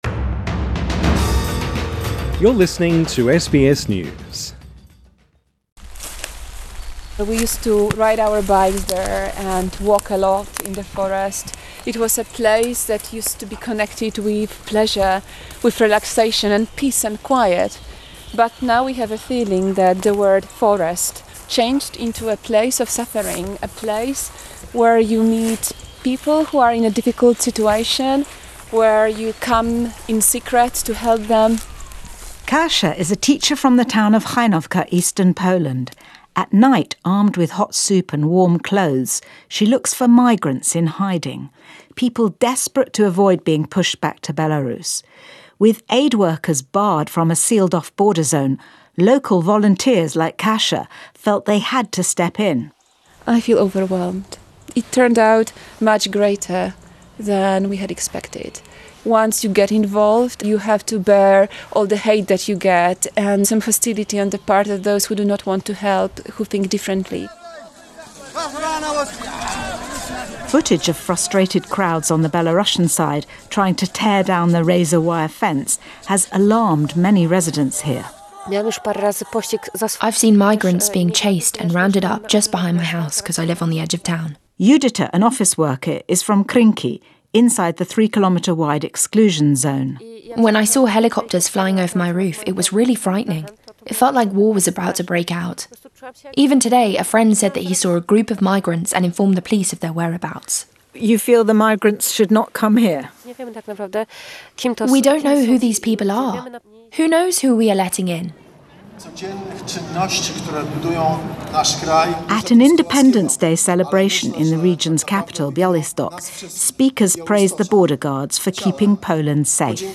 This story was first broadcast on the BBC World Service Share